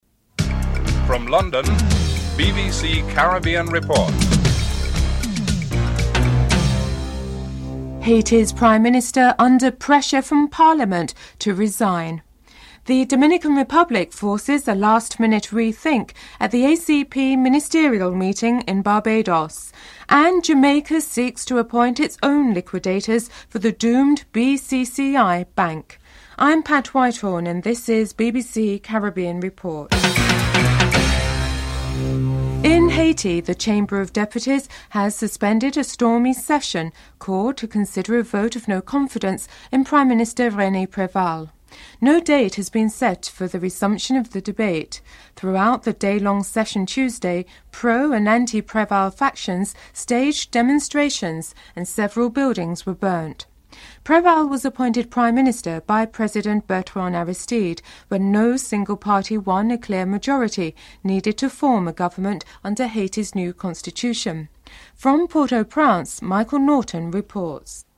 1. Headlines (00:00-00:32)
Interview with G. Arthur Brown, Governor of the Central Bank in Jamaica (06:35-09:18)